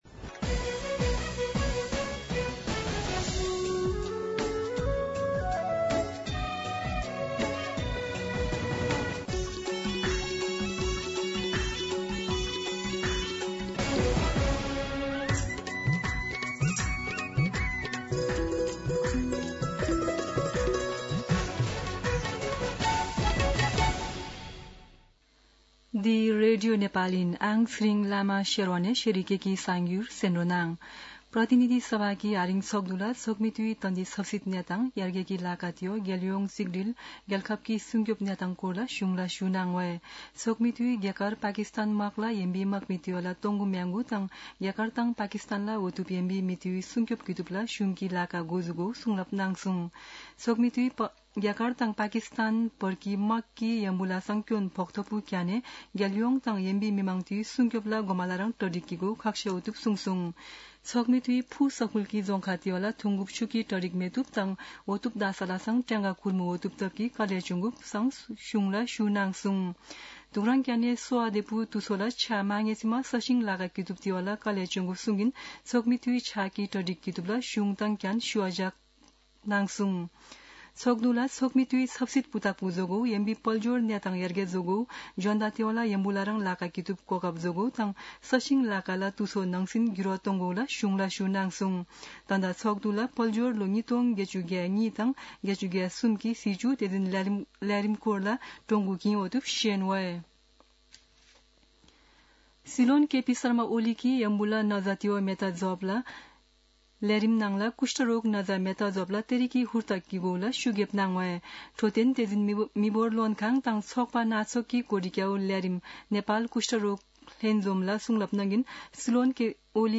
शेर्पा भाषाको समाचार : २५ वैशाख , २०८२
Sherpa-News-1.mp3